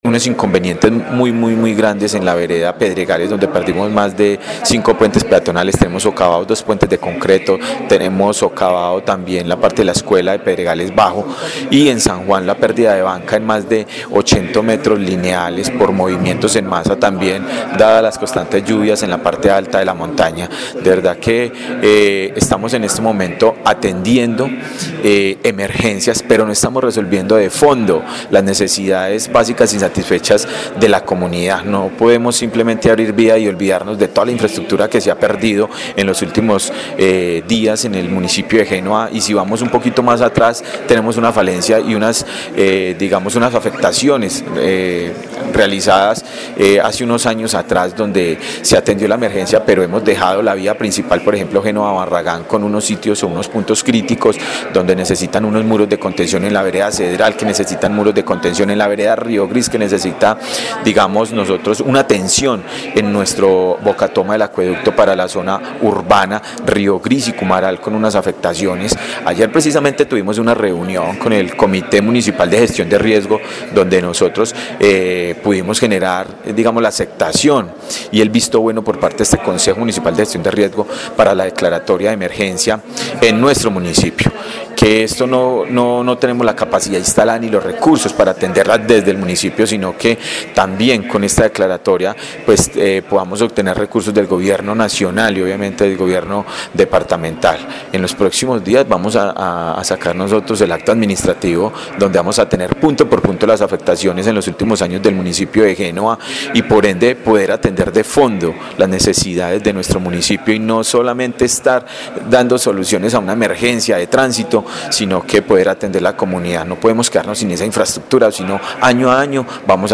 Diego Fernando Sicua, Alcalde de Génova, Quindío